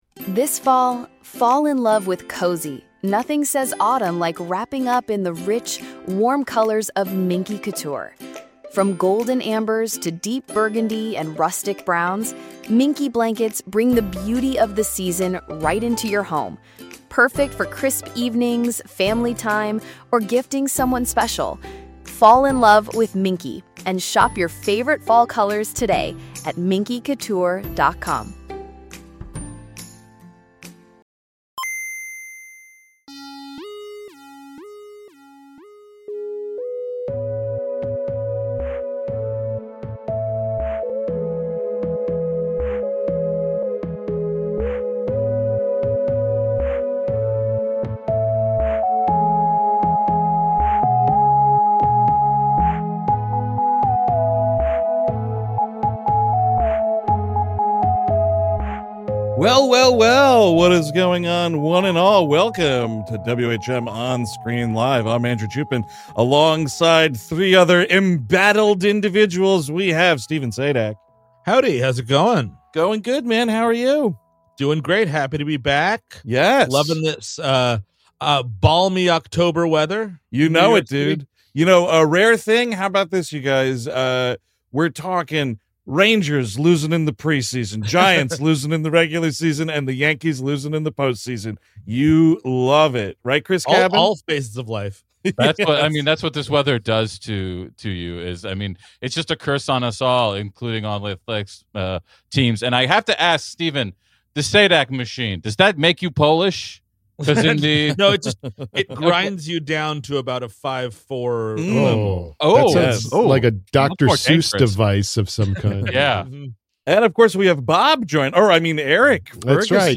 Originally live-streamed Monday, October 6, 2025